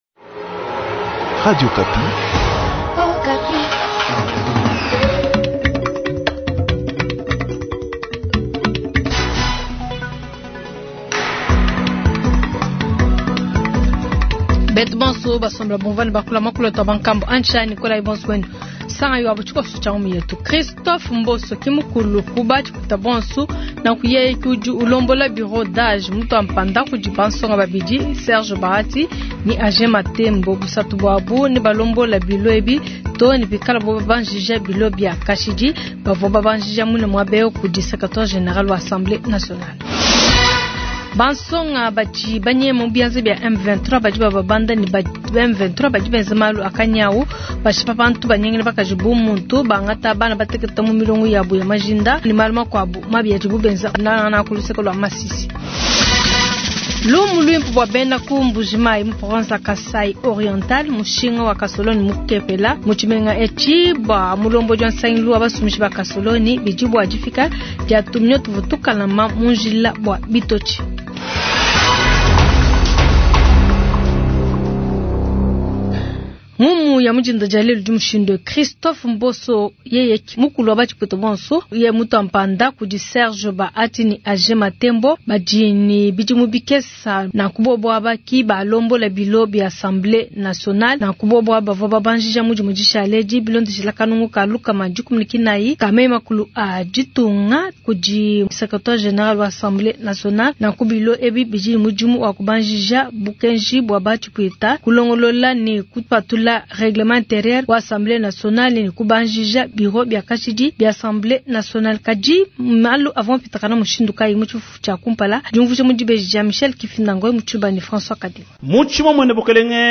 Journal du Mardi 300124